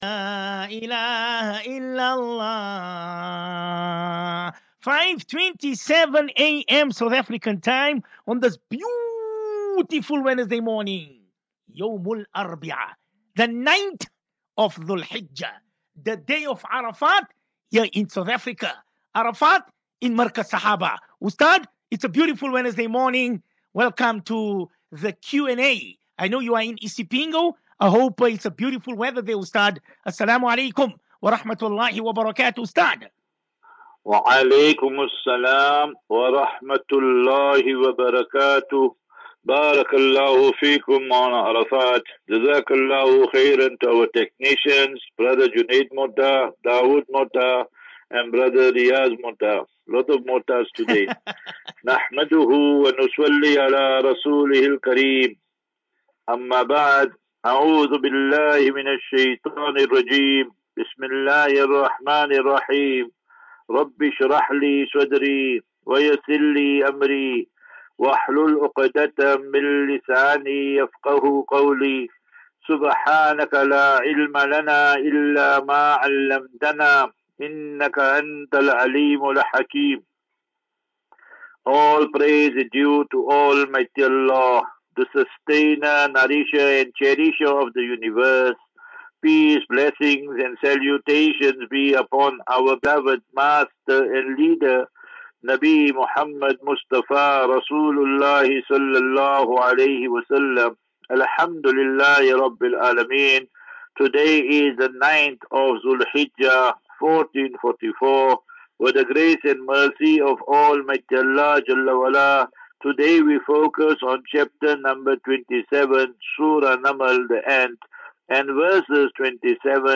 Daily Naseeha.
As Safinatu Ilal Jannah Naseeha and Q and A 28 Jun 28 June 2023 Assafinatu